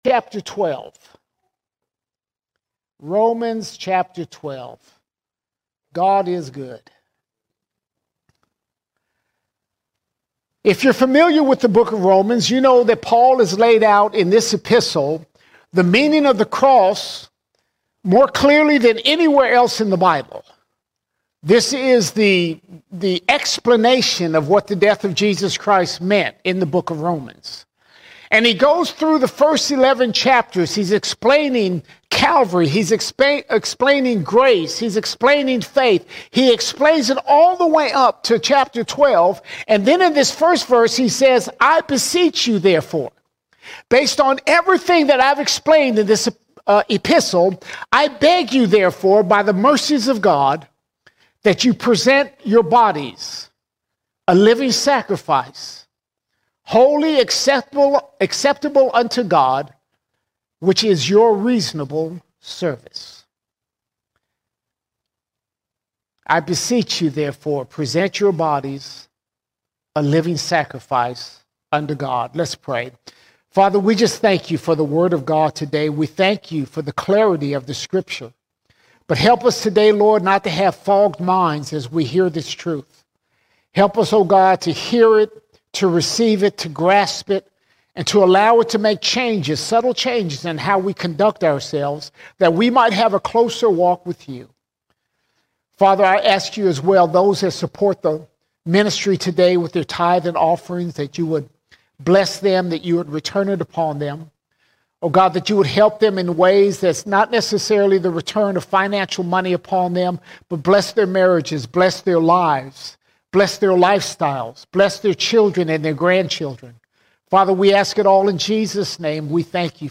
3 March 2025 Series: Sunday Sermons All Sermons Give God Your Body Give God Your Body God desires more than words, He calls us to honor Him with our whole bodies.